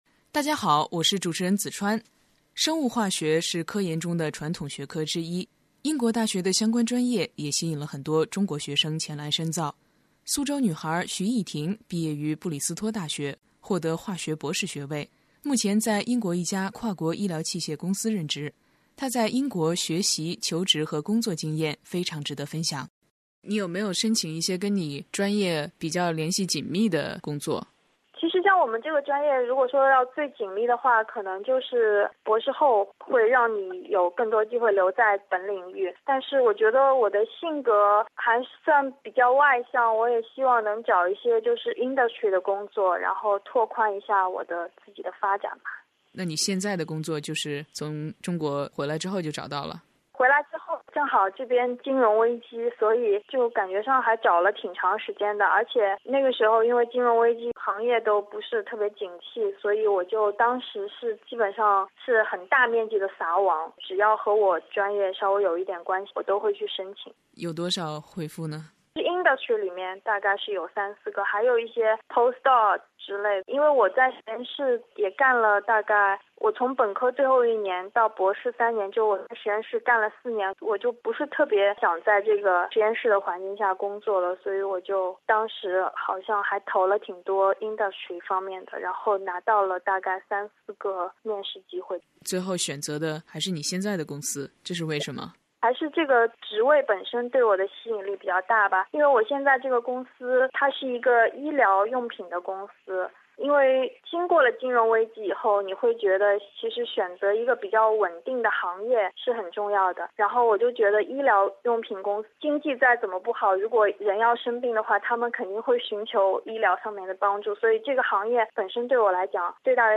专访学生、教师及教育专家，提供权威、实用的留学信息和解答。